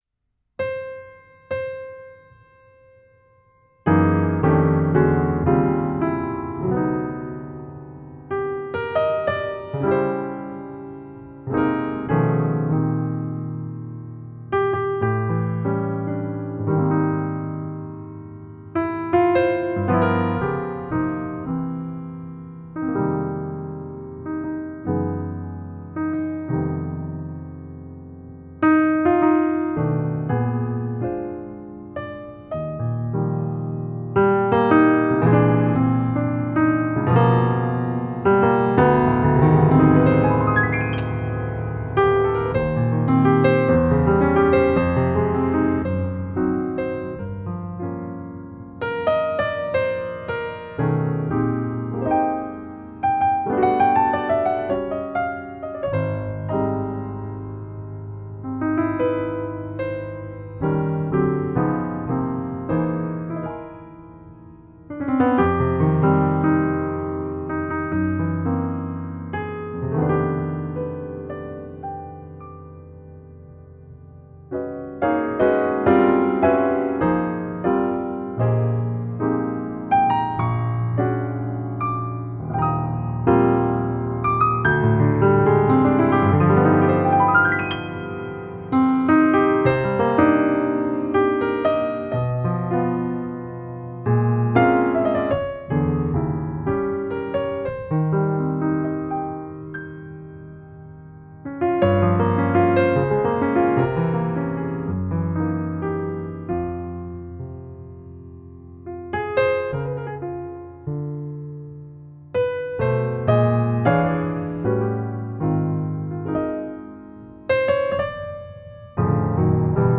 the art of background music